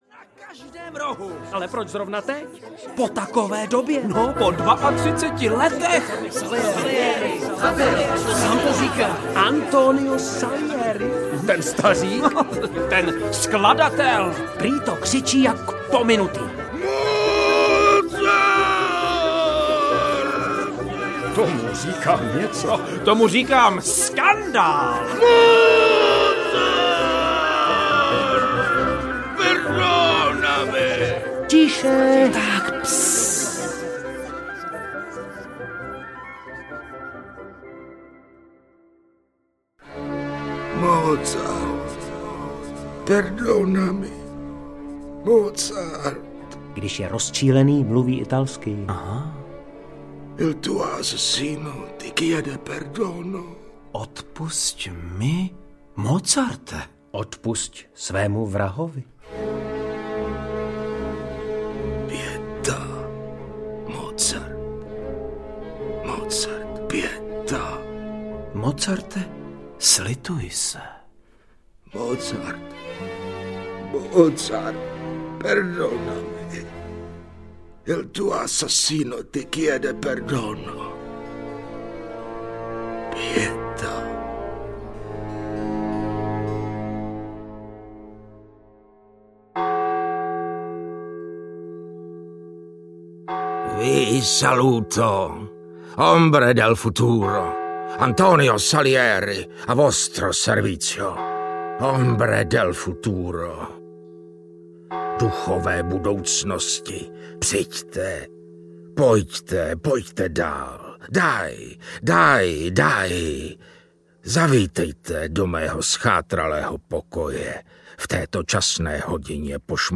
AMADEUS audiokniha
Ukázka z knihy